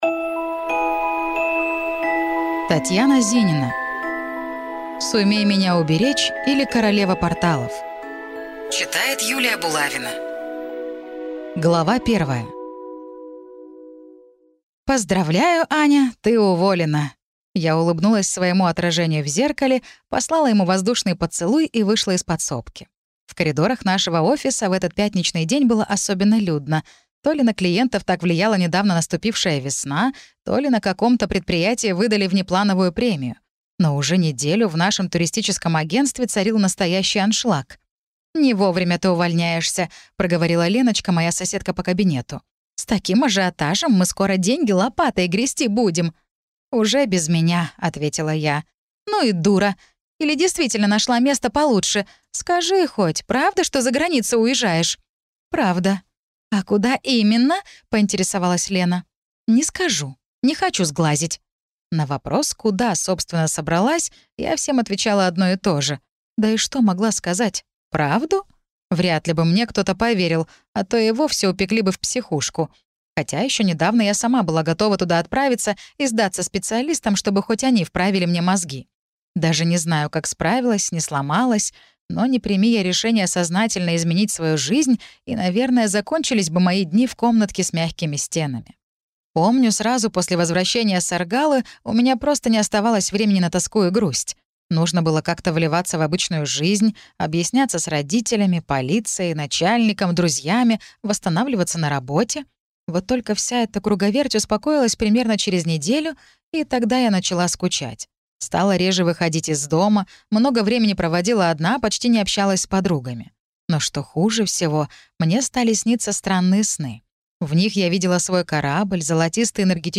Баллада о нефритовой кости. Книга 3 (слушать аудиокнигу бесплатно) - автор Цан Юэ